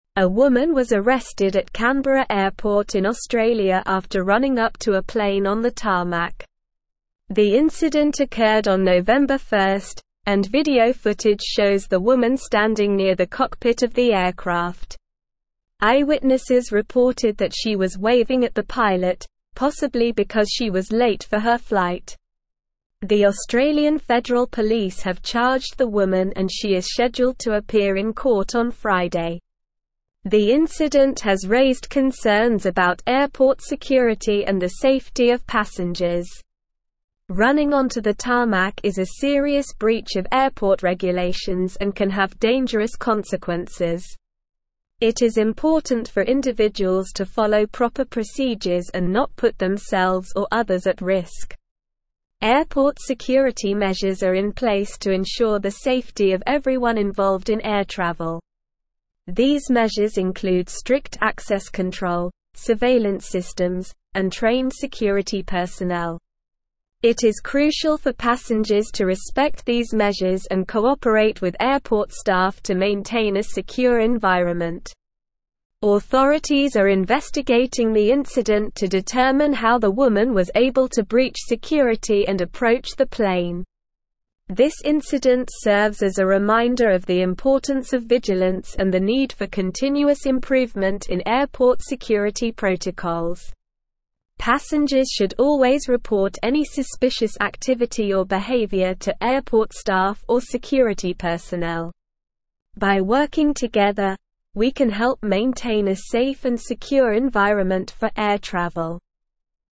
Slow
English-Newsroom-Advanced-SLOW-Reading-Woman-Arrested-for-Running-onto-Tarmac-at-Canberra-Airport.mp3